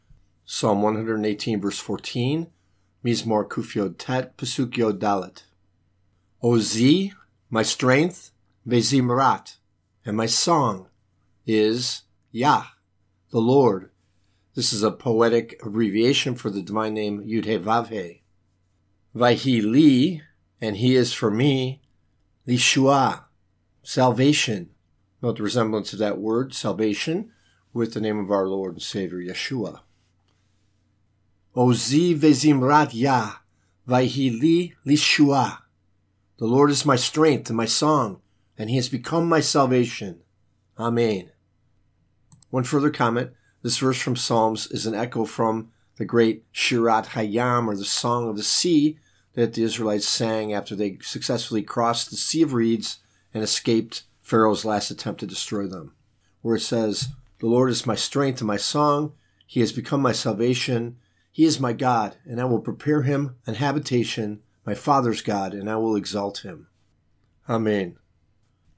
Psalm 118:14 Hebrew reading (click):
Psalm 118:14 Hebrew Lesson